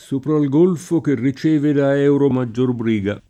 S1pra l g1lfo ke rri©%ve da $uro maJJor br&ga] (Dante) — sim. il pers. m. e cogn. E.